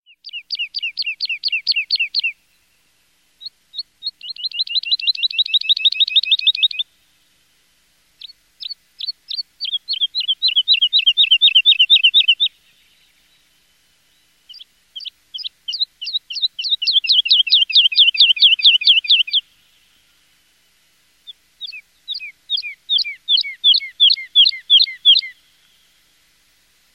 alouette-des-champs.mp3